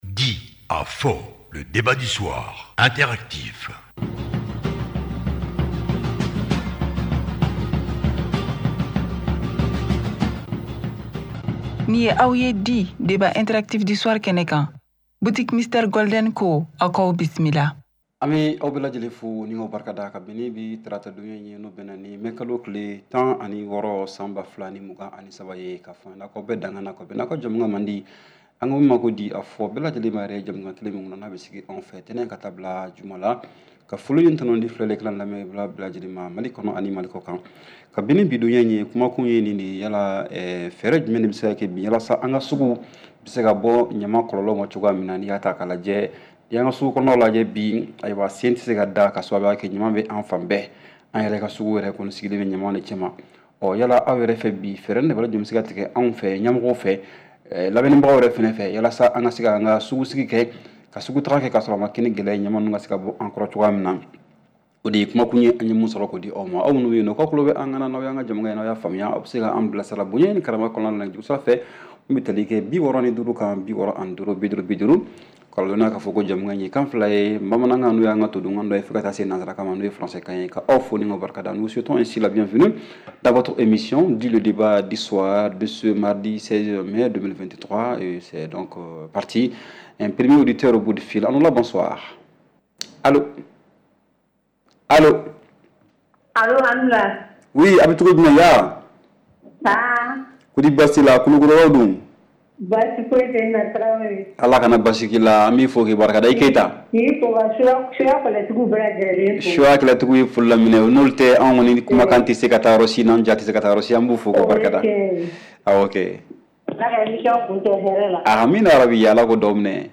REPLAY 16/05 – « DIS ! » Le Débat Interactif du Soir